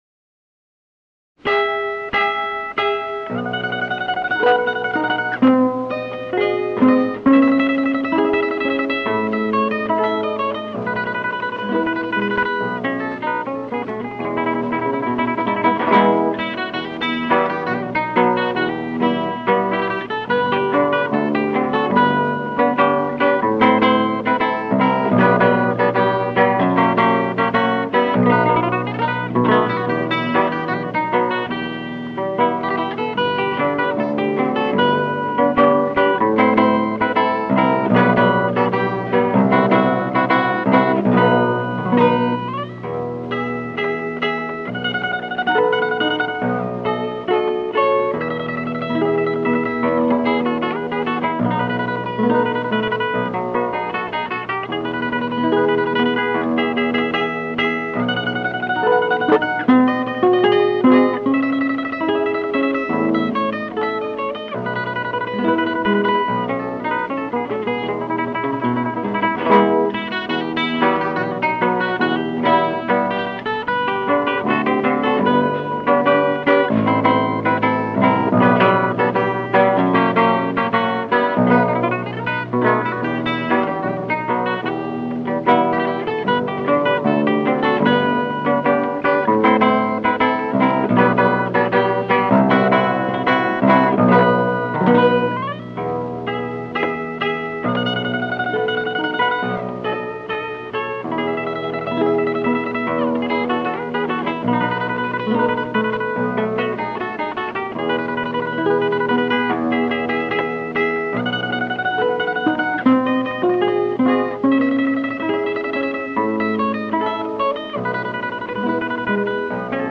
The celebrated Italian harp-guitarist